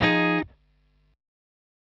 Fbm7.wav